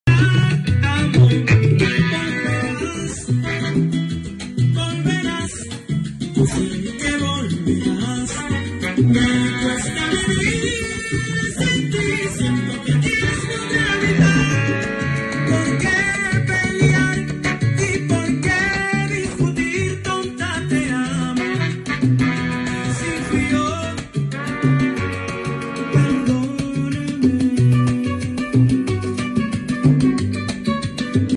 Prueba De Medio Bajo Sound Effects Free Download